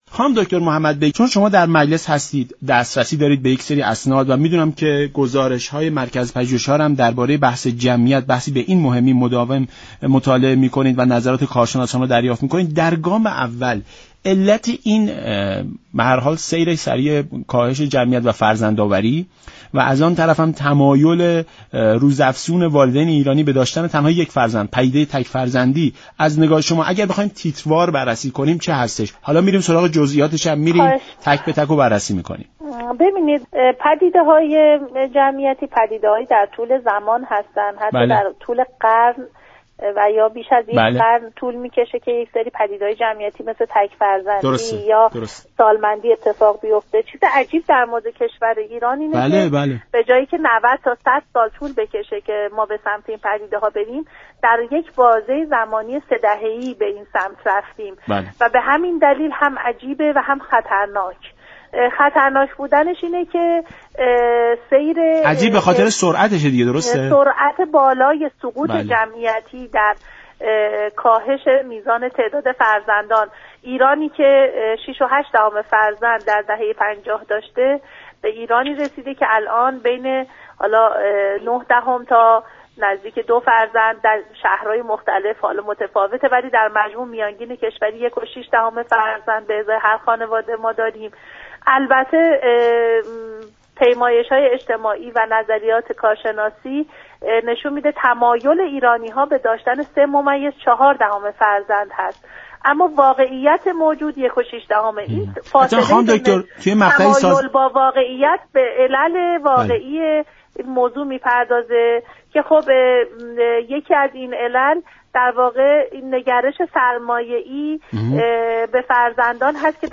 نایب اول رییس كمیسیون بهداشت مجلس در برنامه ایران امروز گفت: در دهه 50 فرزندان سرمایه خانواده بودند؛ امروز اما نگاه هزینه‌ای به فرزندان دارند.